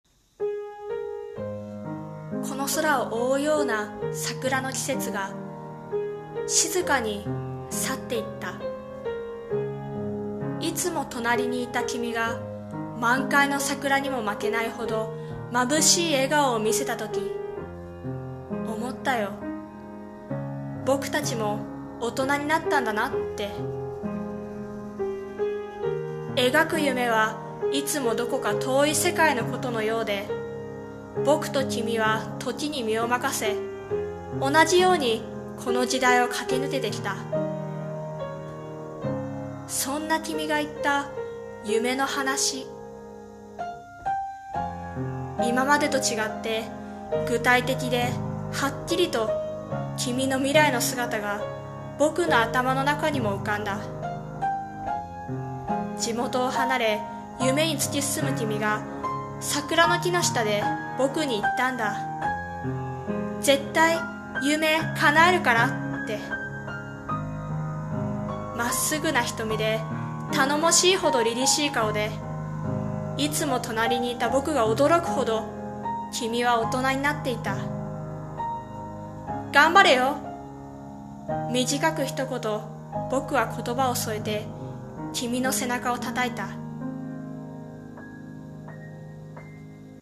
さんの投稿した曲一覧 を表示 声劇【大人】※友情声劇